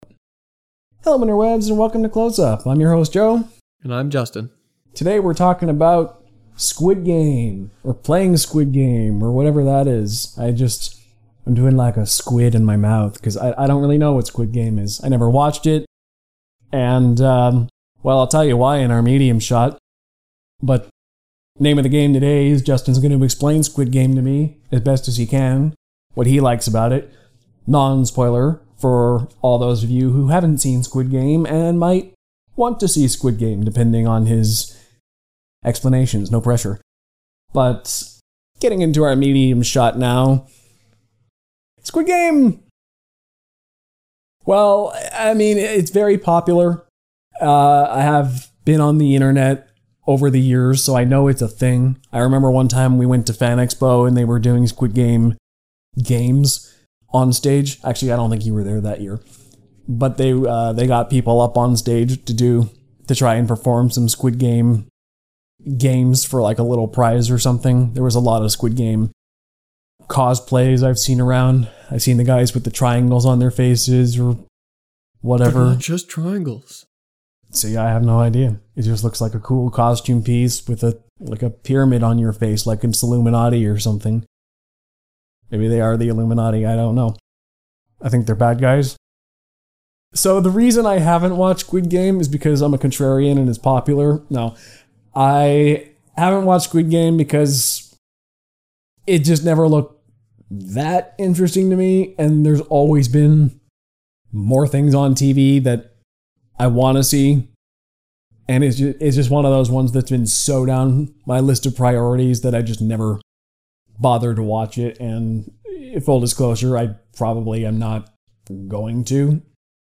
Film, TV, and comic book discussions abound! So does laughter, passionate debate, and thoughtful discussion.